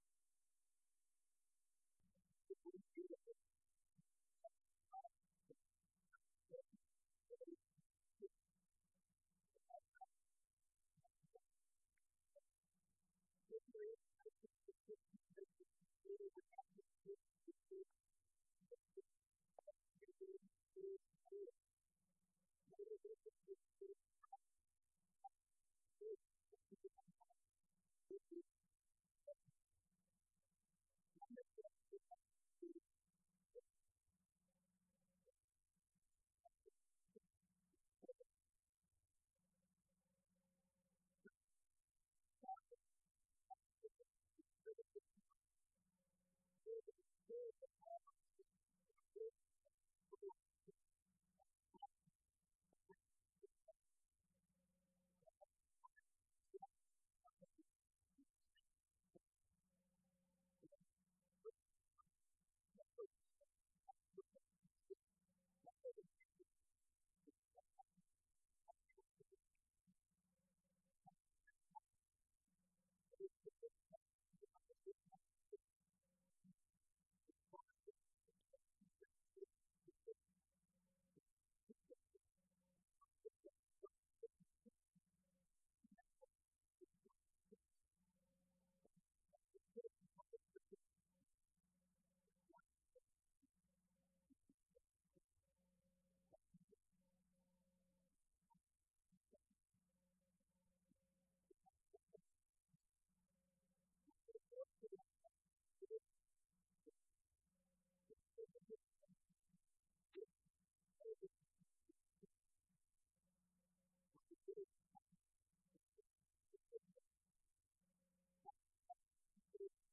Event: 6th Annual Southwest Spiritual Growth Workshop
Filed Under (Topics): Youth Sessions